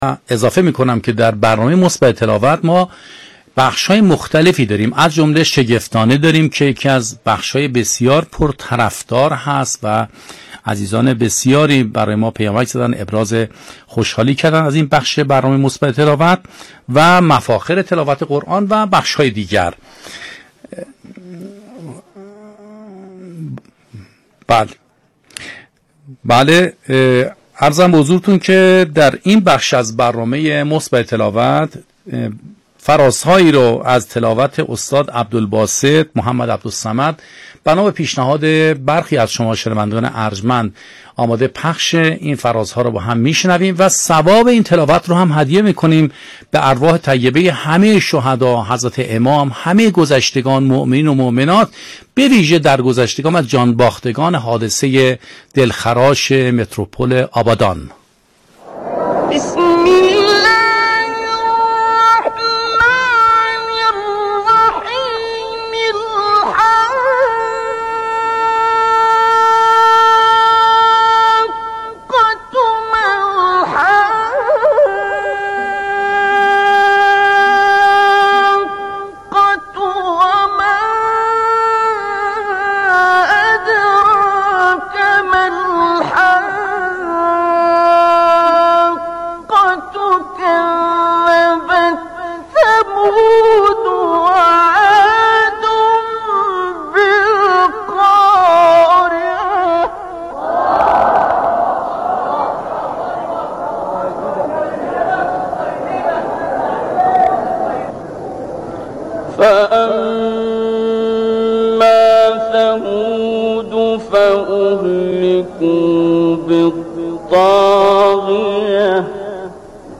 مثبت تلاوت، برنامه عصرگاهی رادیو قرآن است که طی آن به پخش فرازهایی از تلاوت‌هایی قاریان مشهور ایران و جهان اسلام پرداخته می‌شود.
پخش ابتهال، تواشیح و قطعات درخواست شده از سوی مخاطبان، گفت‌وگو با صاحب‌نظران و فعالان قرآنی، بخش شگفتانه و معرفی مفاخر تلاوت قرآن، «یک آیه، یک نکته» و اجرای مسابقه از جمله آیتم‌های این برنامه 50 دقیقه‌ای است.